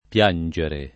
pL#nJere] v.; piango [pL#jgo], -gi — pass. rem. piansi [pL#nSi]; part. pass. pianto [pL#nto] — ant. o poet. piagnere: piango, ‑gni; con -gn- [n’n’] invece di -ng- [nJ] davanti a tutte le desinenze che comincino per e o per i